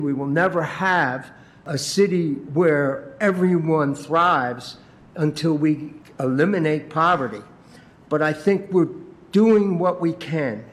Commissioner Don Cooney also pointed out that they are spending more to help low income residents than they ever have, and a lot more than they used to.